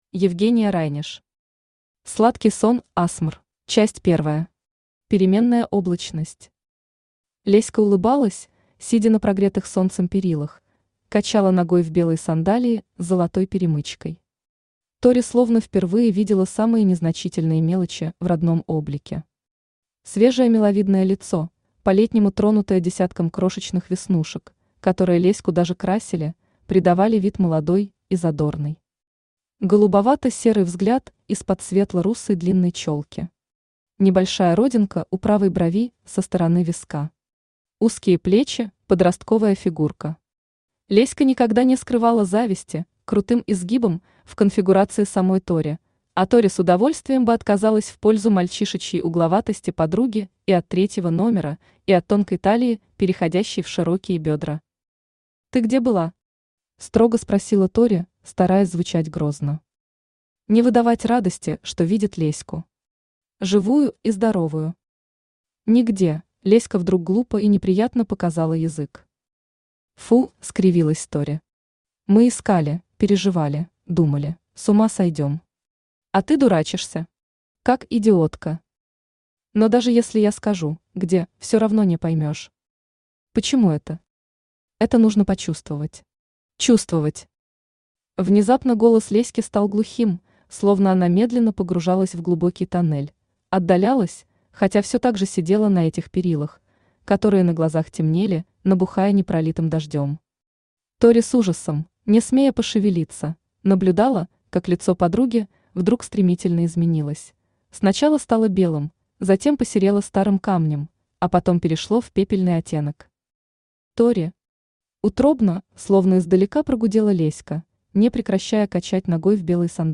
Аудиокнига Сладкий сон АСМР | Библиотека аудиокниг
Aудиокнига Сладкий сон АСМР Автор Евгения Райнеш Читает аудиокнигу Авточтец ЛитРес.